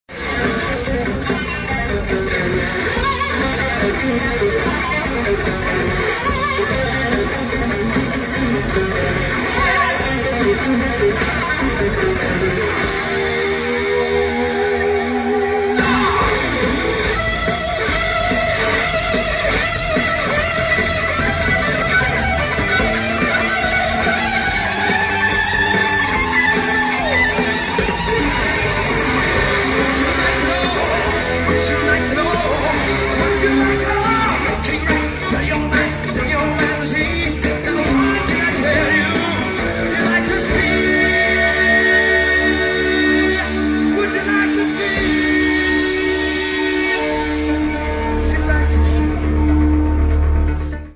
Live Milan